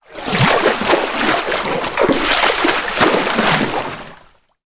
Кормление акулы у поверхности воды, брызги и резкие движения